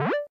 sfx_shieldup.mp3